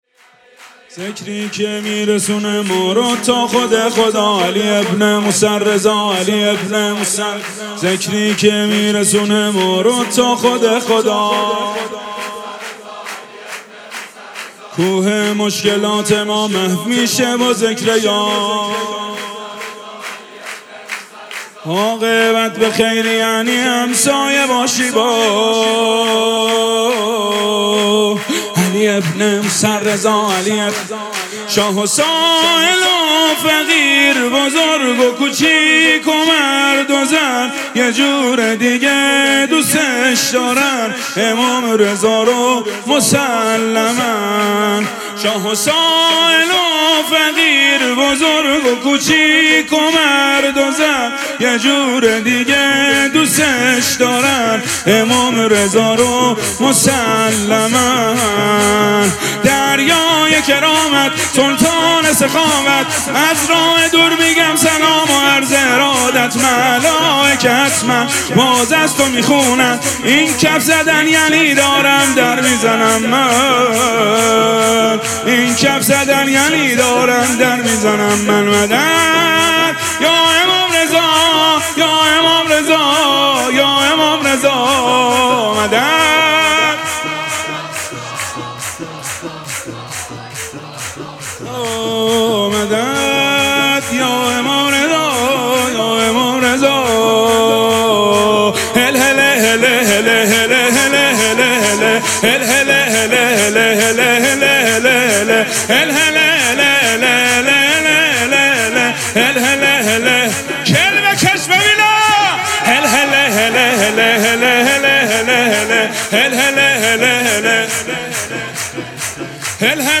مراسم جشن ولادت امام رضا علیه‌السّلام
سرود